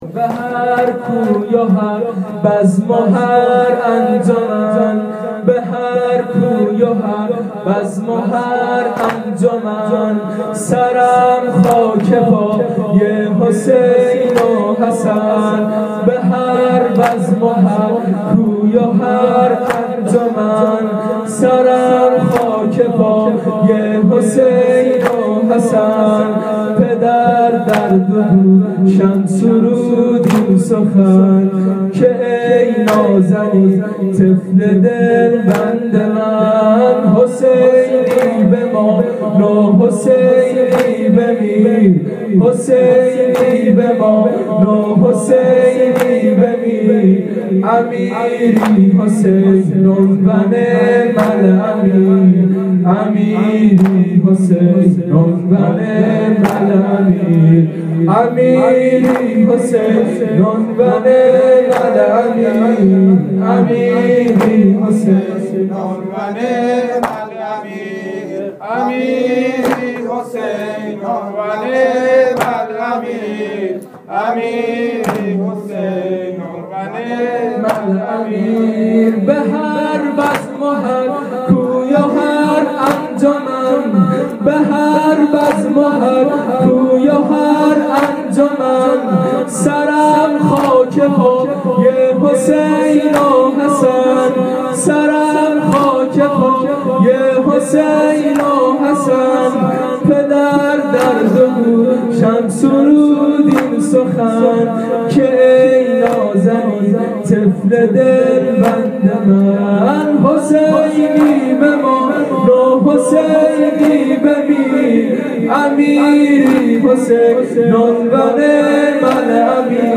وفات حضرت معصومه (92)- واحد